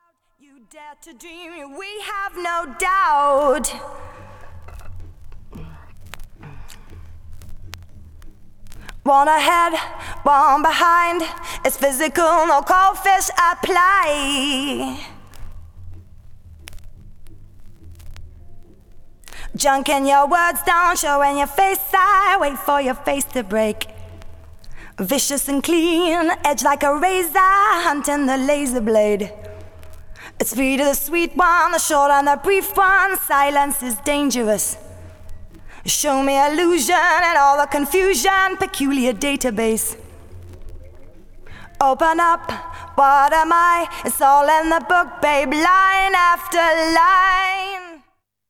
Styl: Drum'n'bass, Jungle/Ragga Jungle
Acapella